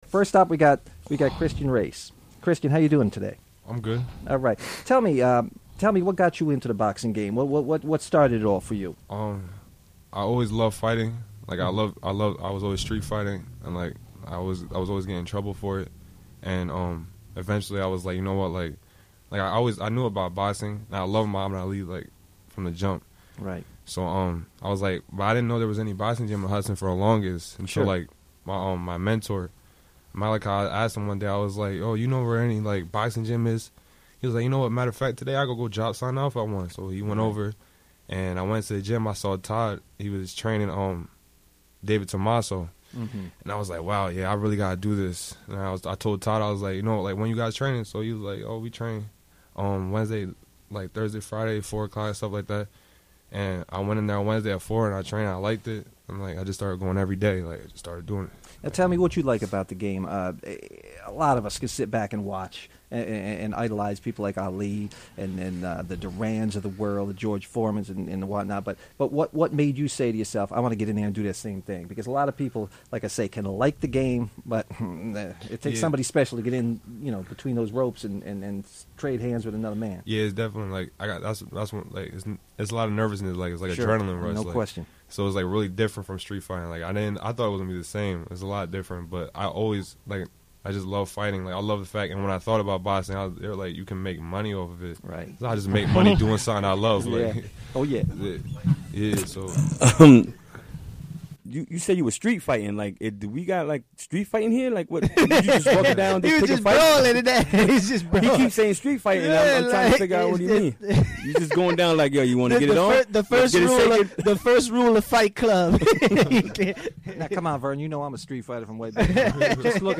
Members of the PHD Boys Boxing Club talk about the sport.
Recorded during the WGXC Afternoon Show Wednesday, February 1, 2017.